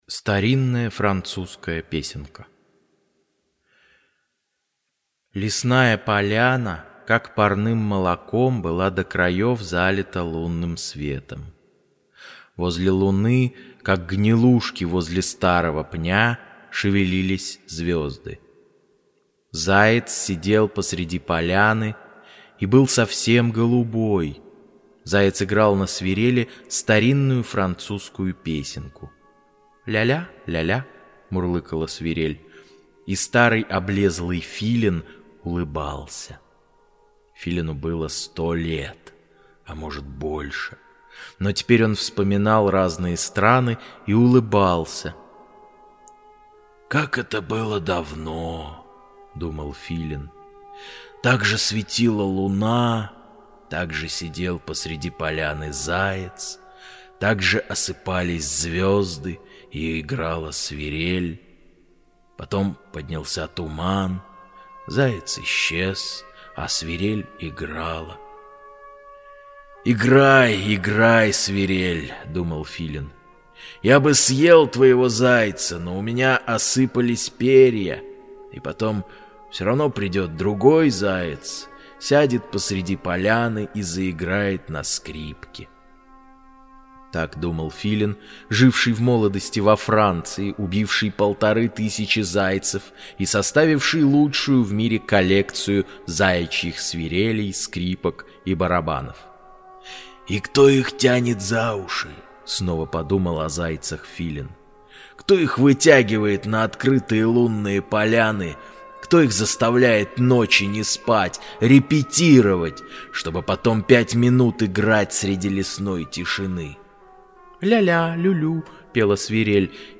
Старинная французская песенка – Козлов С.Г. (аудиоверсия)
Аудиокнига в разделах